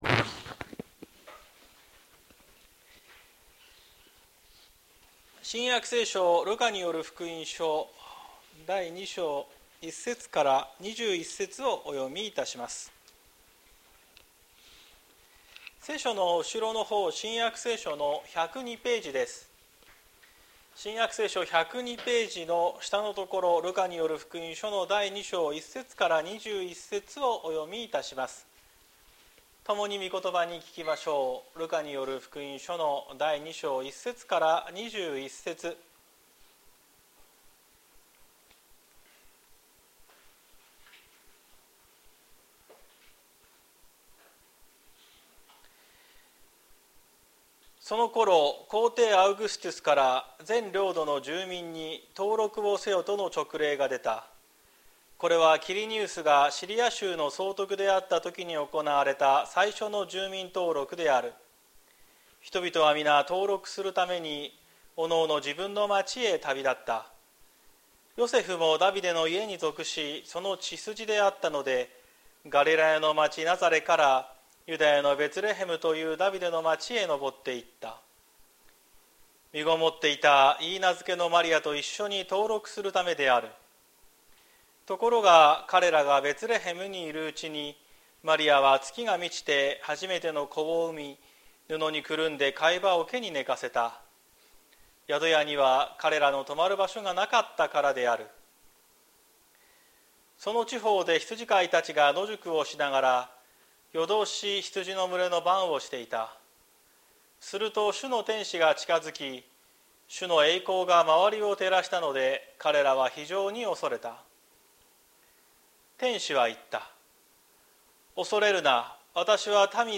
2024年12月22日朝の礼拝「天使たちの歌」綱島教会
説教アーカイブ。